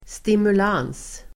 Uttal: [stimul'an:s (el. -'ang:s)]